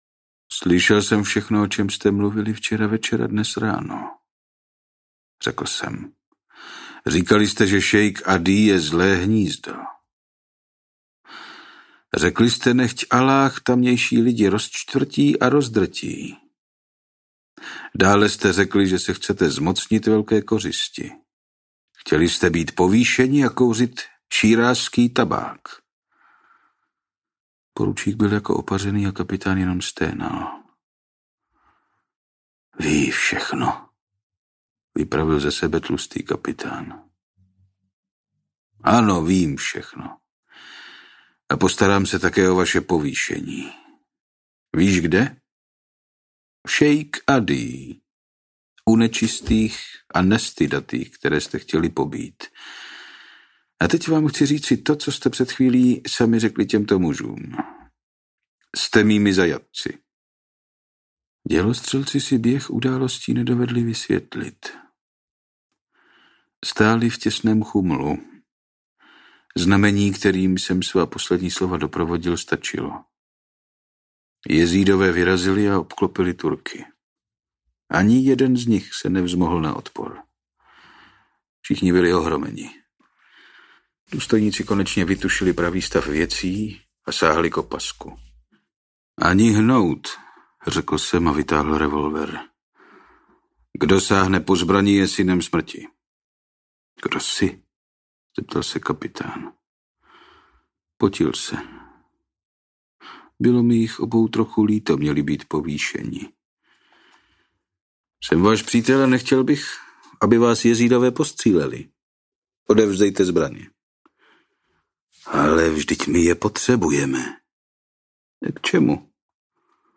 Divokým Kurdistánem audiokniha
Ukázka z knihy